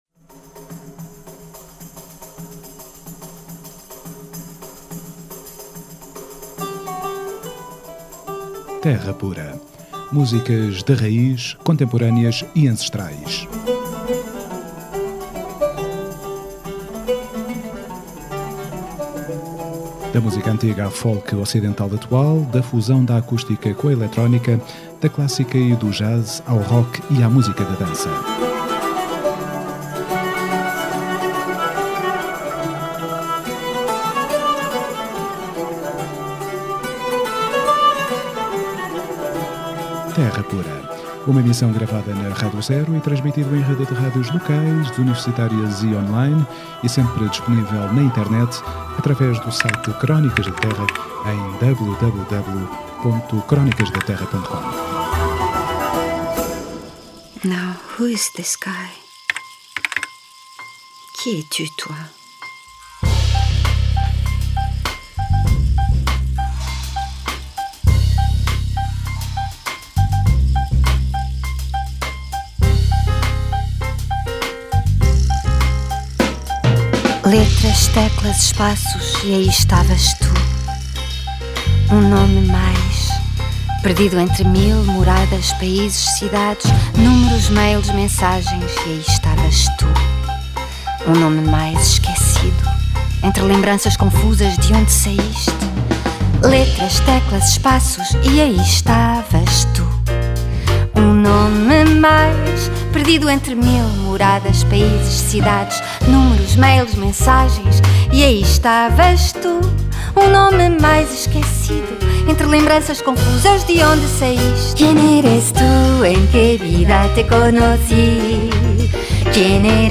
Terra Pura 05MAR14: Entrevista Maria de Medeiros